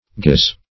gyse - definition of gyse - synonyms, pronunciation, spelling from Free Dictionary Search Result for " gyse" : The Collaborative International Dictionary of English v.0.48: Gyse \Gyse\ (g[imac]z), n. Guise.